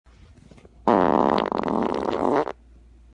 Download Farting sound effect for free.
Farting